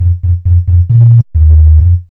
5408L B-LOOP.wav